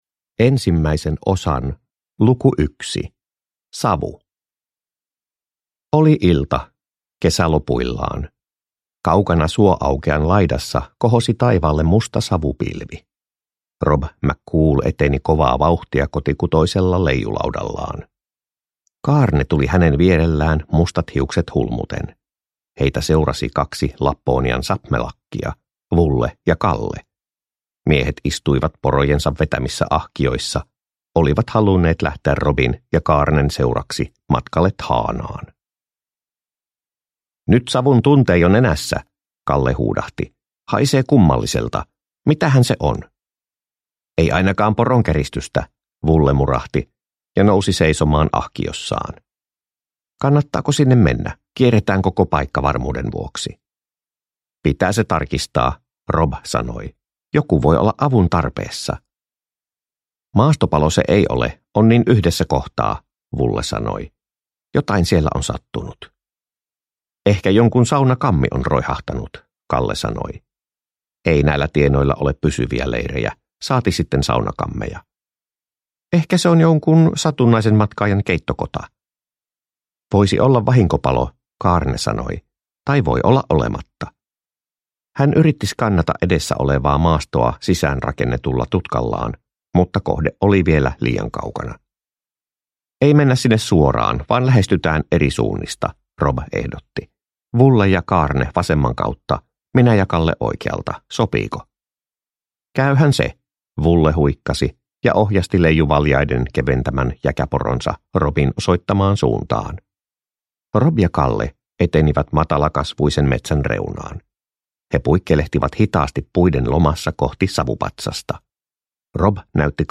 Rob McCool ja Tuonelan avaimet – Ljudbok